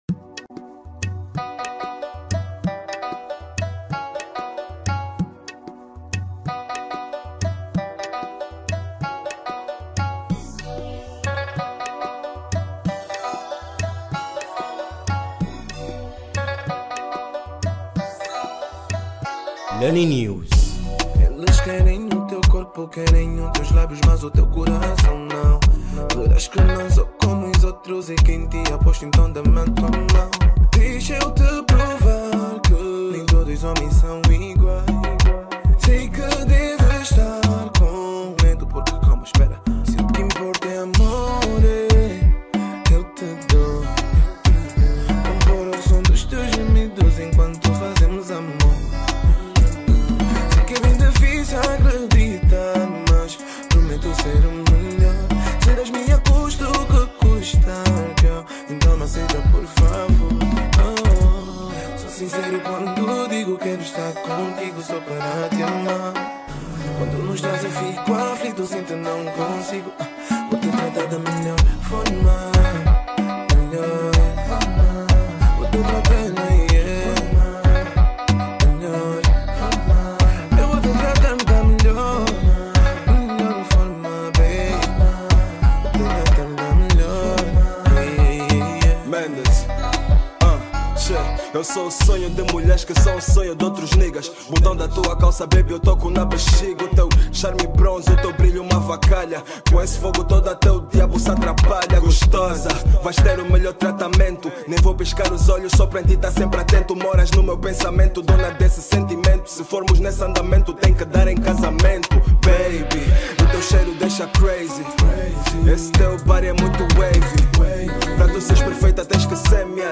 Zouk